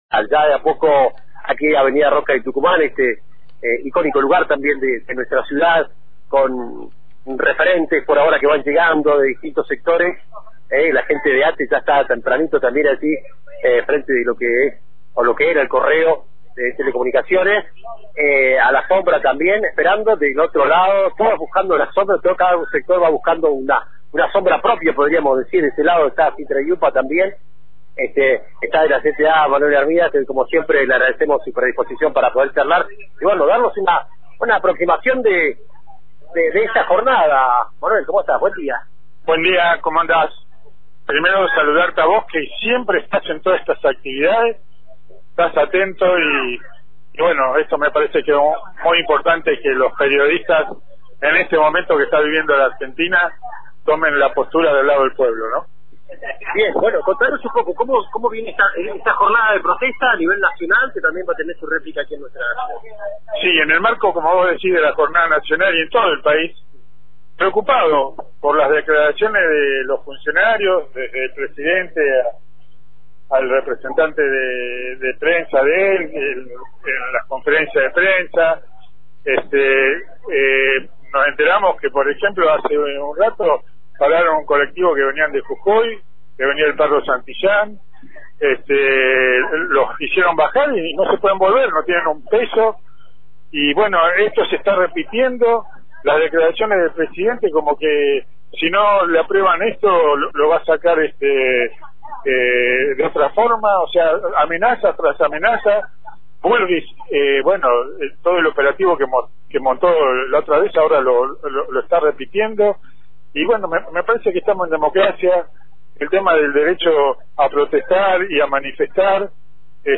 Antena Libre realizó una cobertura especial desde la movilización